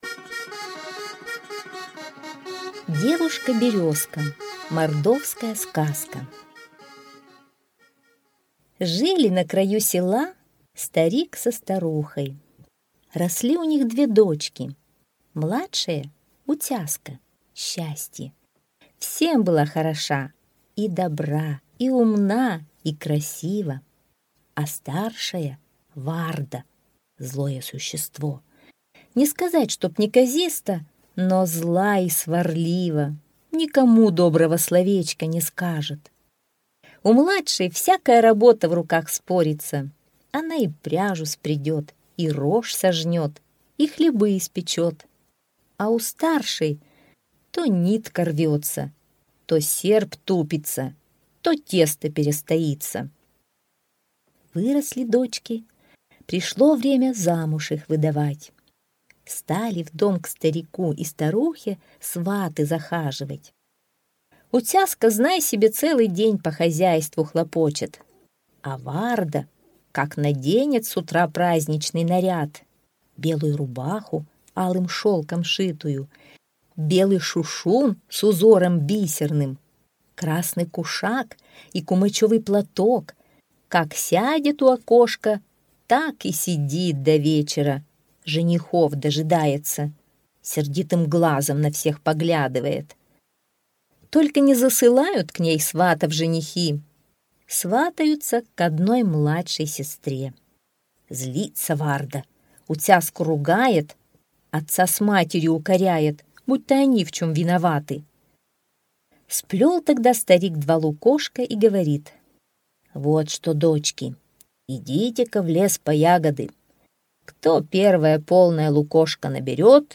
Аудиосказка «Девушка-березка»